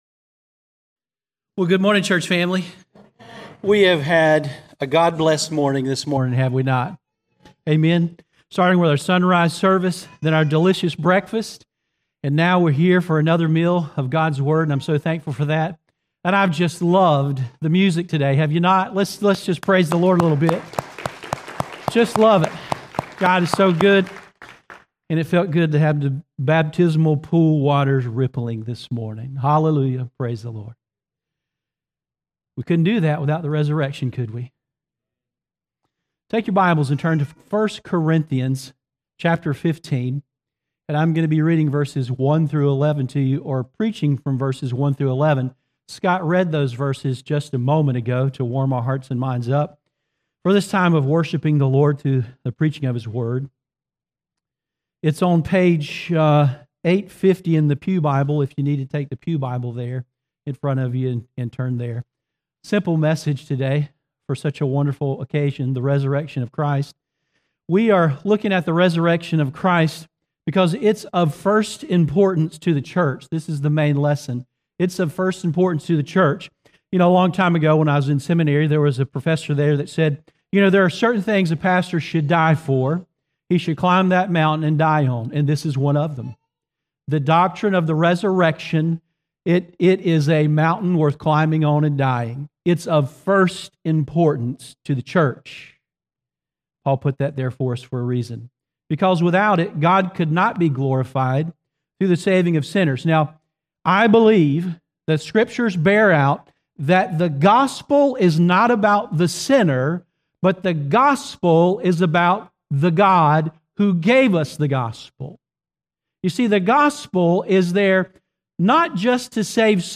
Service Audio